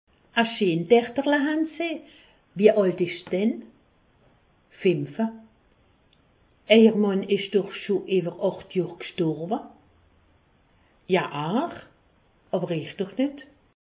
Haut Rhin
Pfastatt